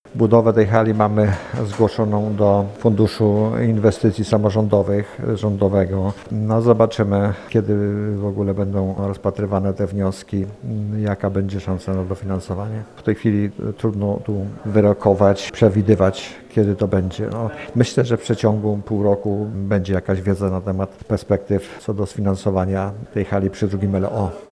Czy projekt ten znajdzie się na liście z możliwością dofinansowania, będziemy musieli na tą odpowiedź jeszcze poczekać, zaznacza starosta mielecki Stanisław Lonczak.